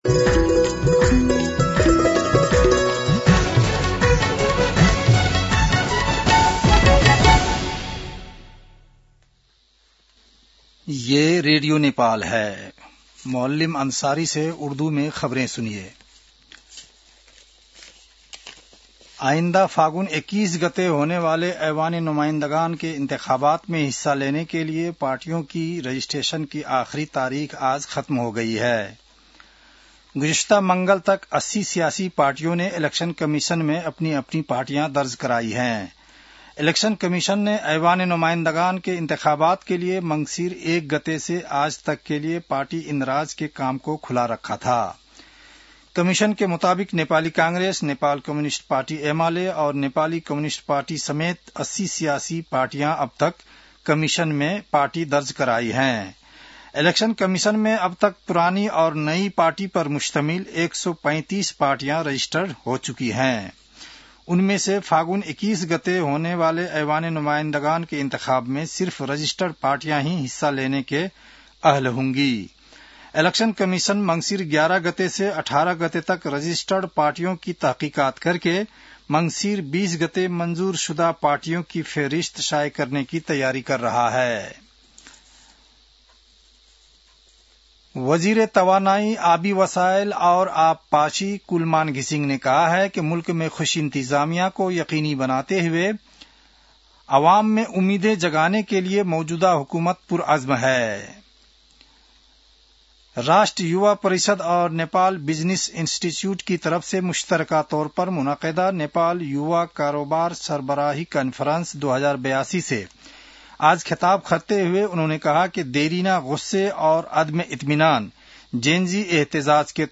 उर्दु भाषामा समाचार : १० मंसिर , २०८२